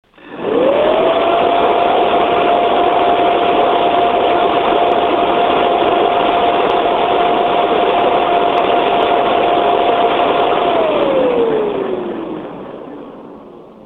After powering up the fan there was no doubt about it - the 38mm thick Delta fan moves a lot of air and creates a lot of noise.
You can listen to this heatsink in operation for yourself by clicking on the headphones and playing the MP3 recording.
FrostyTech Acoustic Sampling Chamber
Globalwin CAK4-86 69.0 dB louder
It is no surprise that the CAK4-86 manages to come in with one of the highest sound level measurements of all the socket A heatsinks we have tested thus far.